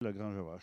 Elle provient de Sallertaine.
Locution ( parler, expression, langue,... )